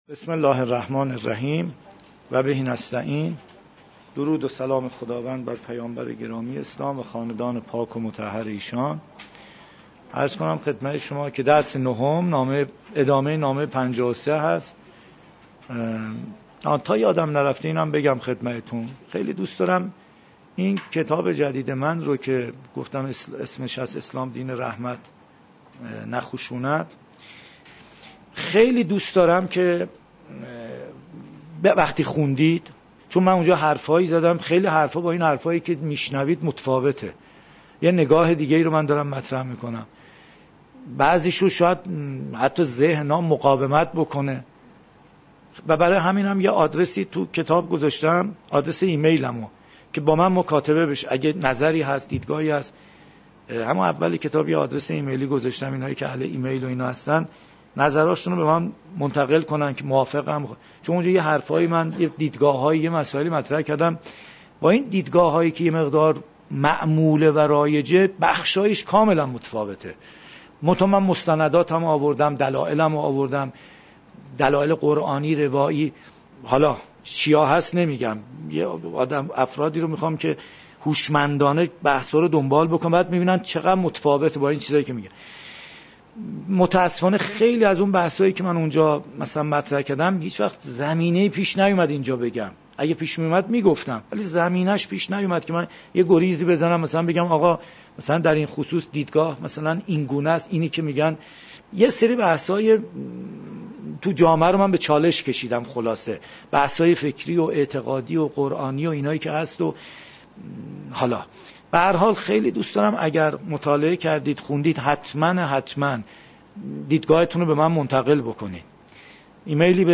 126 - تلاوت قرآن کریم